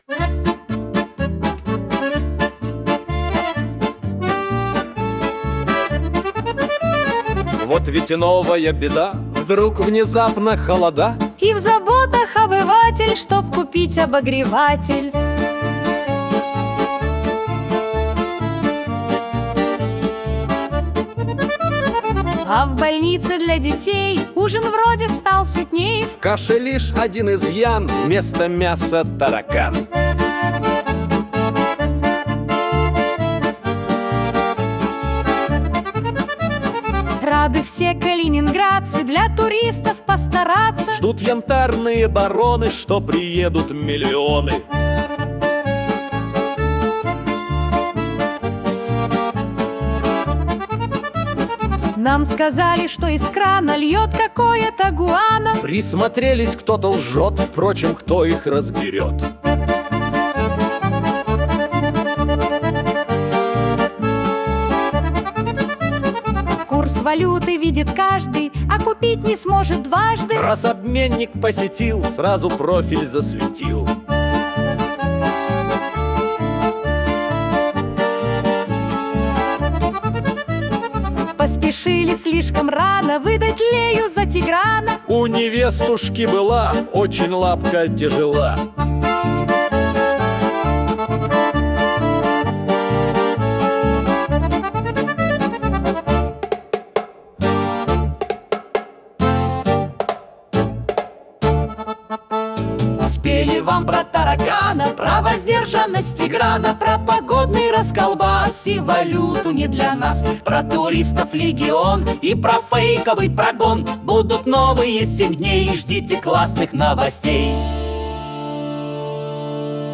Комические куплеты о главных и важных событиях